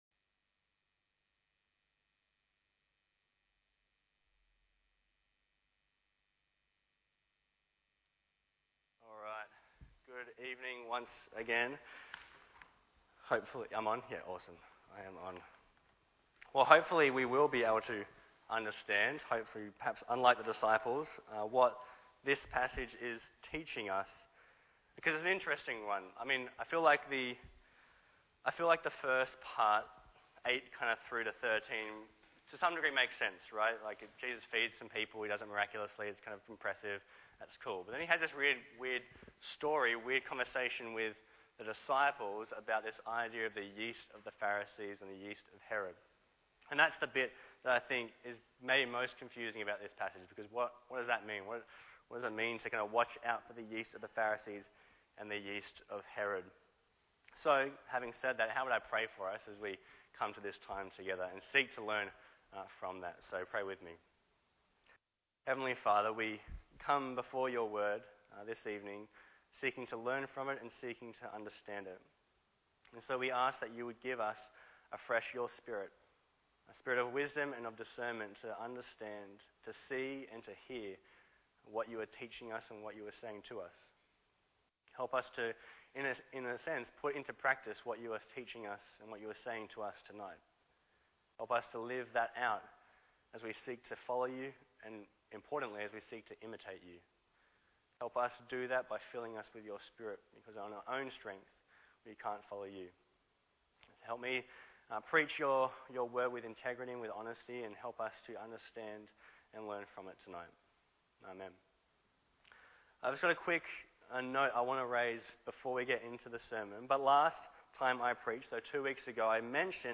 Bible Text: Mark 8:1-21 | Preacher